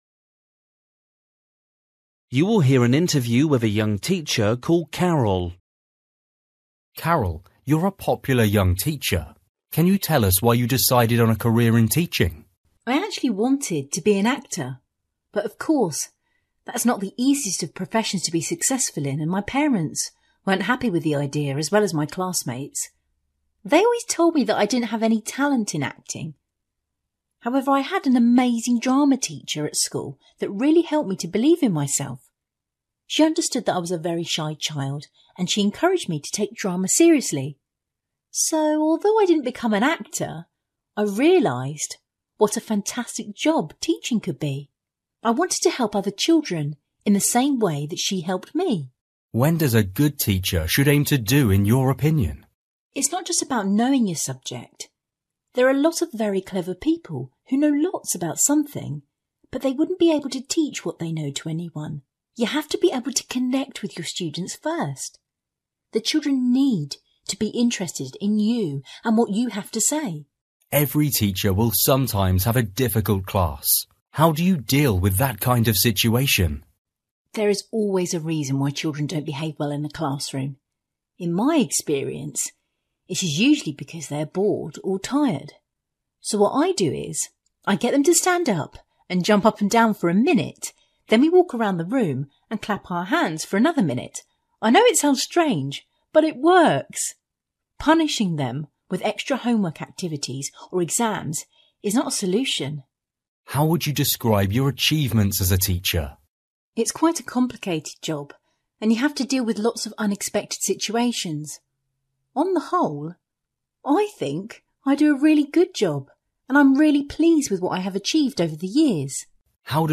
Listening: a young teacher